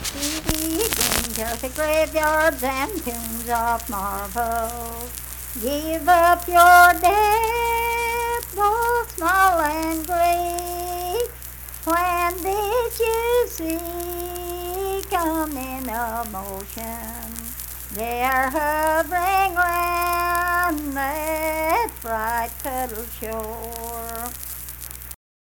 Unaccompanied vocal music performance
Verse-refrain 1(4).
Voice (sung)